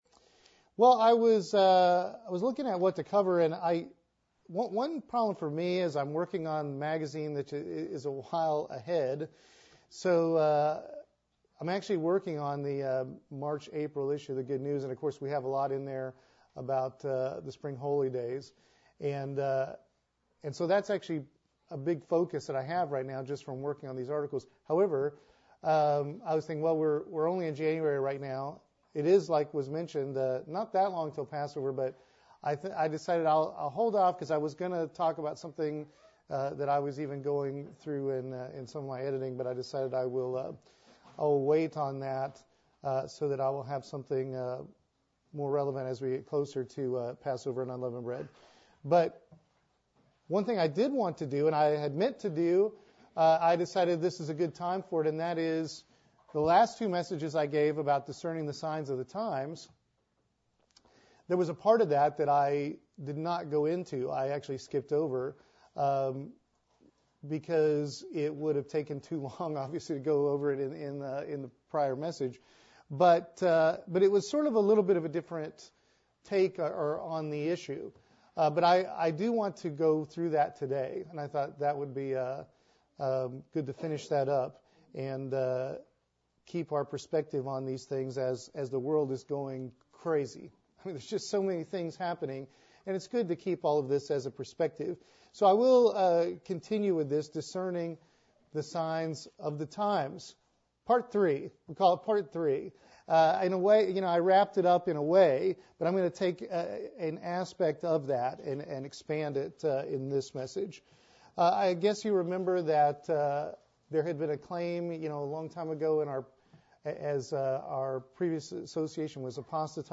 Given in Columbia - Fulton, MO
UCG Sermon Studying the bible?